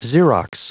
Prononciation du mot xerox en anglais (fichier audio)
Prononciation du mot : xerox